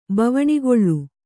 ♪ bavaṇigoḷḷu